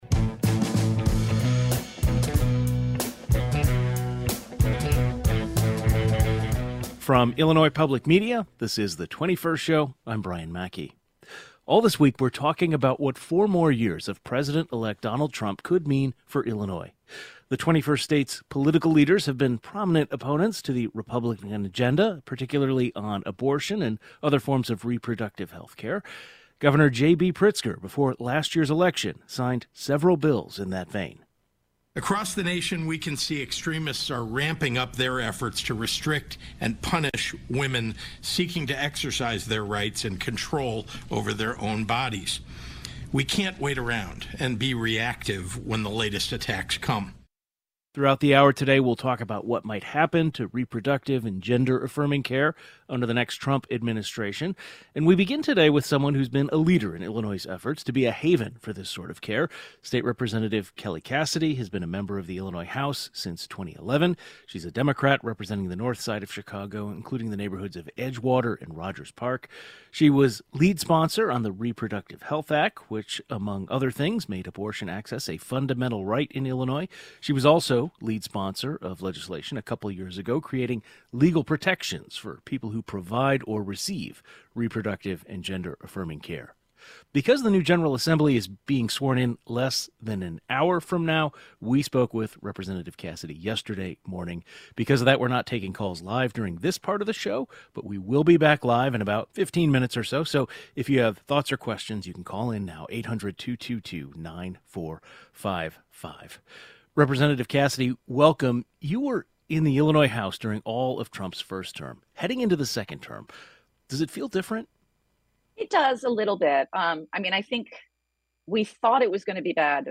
Four More Years: State Rep. Kelly Cassidy discusses Illinois protections for reproductive, transgender healthcare